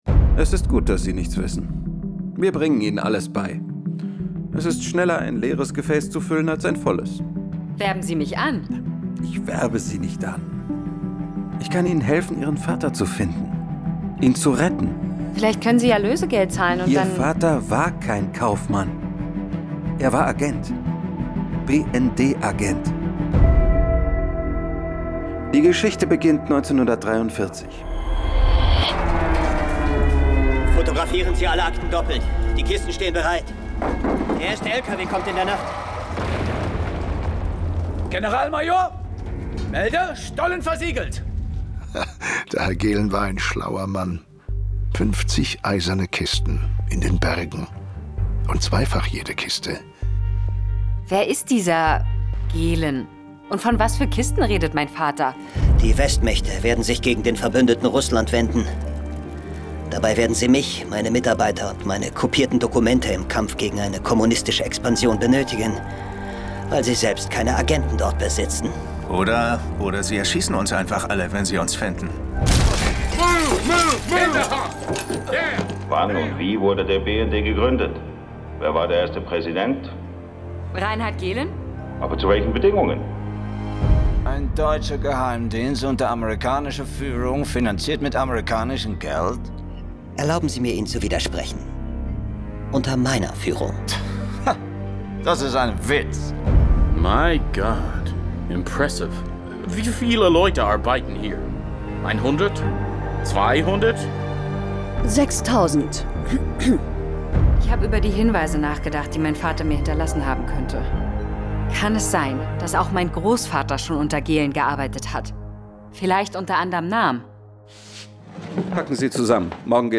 Hörspiel "Die größten Fälle des BND" | Audio Quants
Trailer